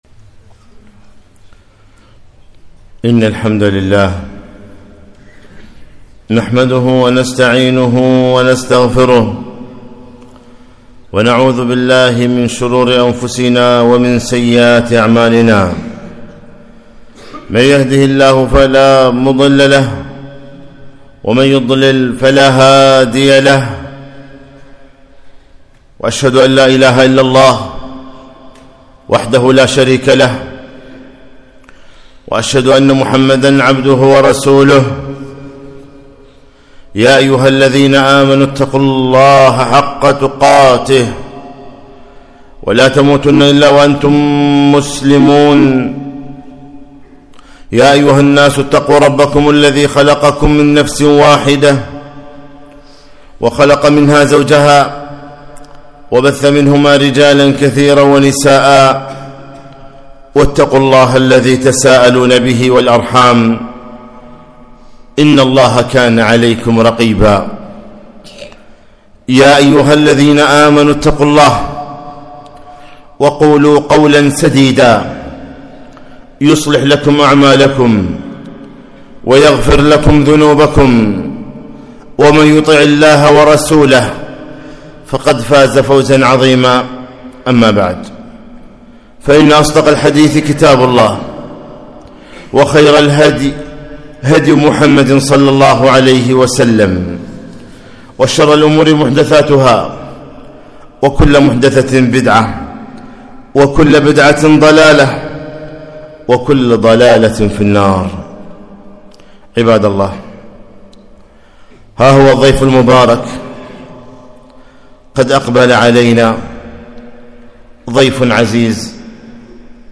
خطبة - جاءكم شهر رمضان